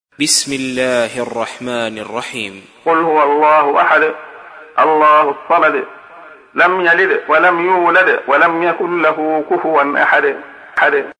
تحميل : 112. سورة الإخلاص / القارئ عبد الله خياط / القرآن الكريم / موقع يا حسين